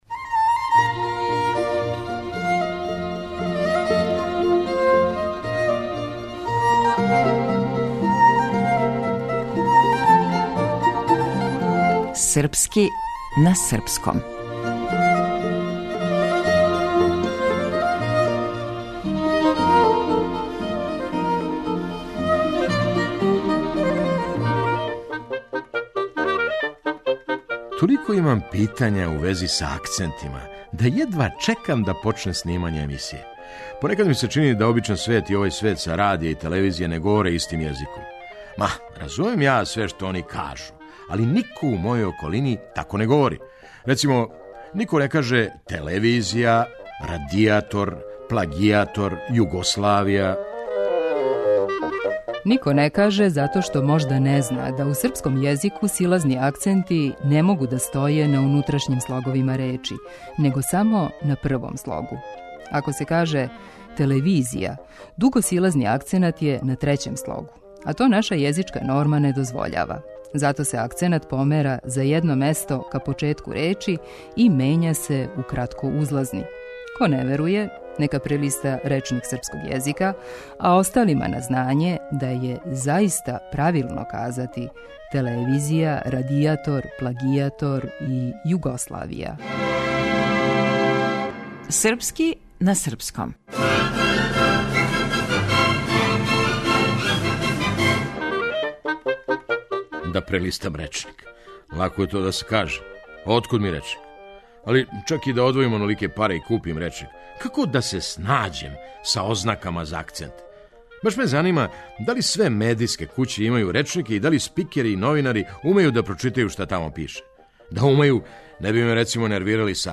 Дође ми да замолим организаторе разних фестиВАла да нађу неко друго име за ту врсту манифестације, све док новинари не науче како се правилно каже – ФЕстивал или фесТИвал.
Драмски уметник: Феђа Стојановић